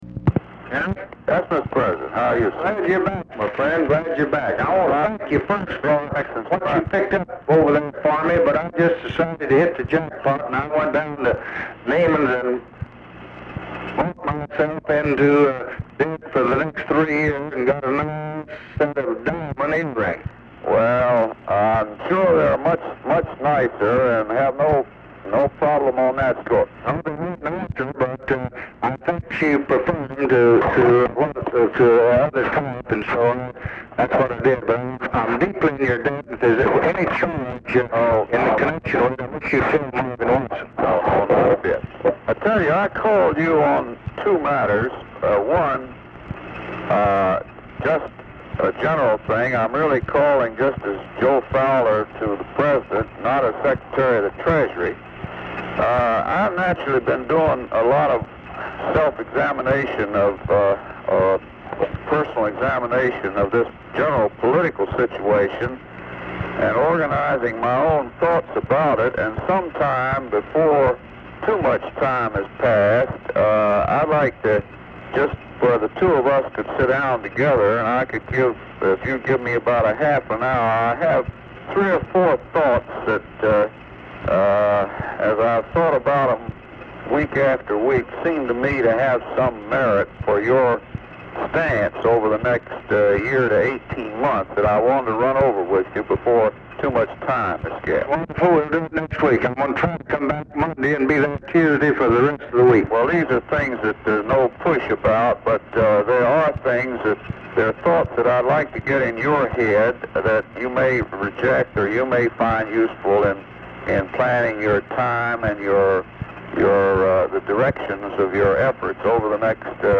Telephone conversation # 11167, sound recording, LBJ and HENRY "JOE" FOWLER
POOR SOUND QUALITY
Format Dictation belt
Location Of Speaker 1 LBJ Ranch, near Stonewall, Texas